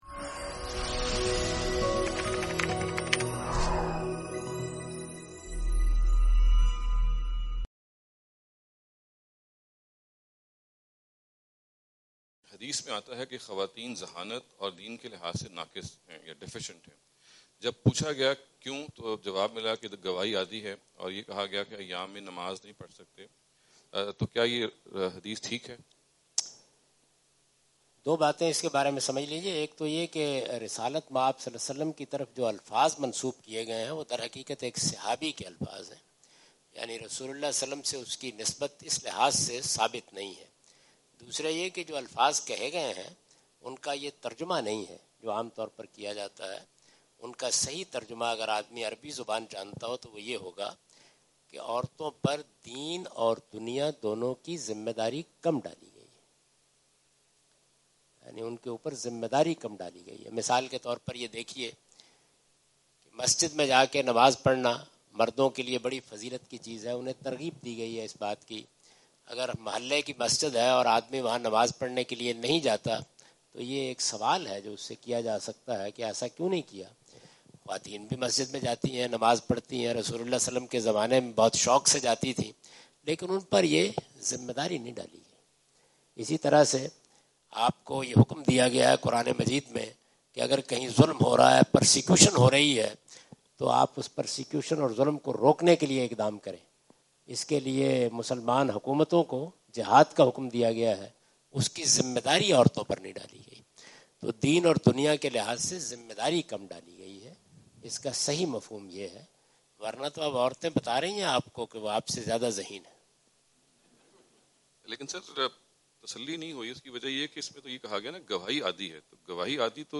Javed Ahmad Ghamidi answer the question about "Are Women Less Intelligent than Men?" during his US visit on June 13, 2015.
جاوید احمد غامدی اپنے دورہ امریکہ 2015 کے دوران سانتا کلارا، کیلیفورنیا میں "کیا خواتین مردوں سے کم ذہانت رکھتی ہیں؟" سے متعلق ایک سوال کا جواب دے رہے ہیں۔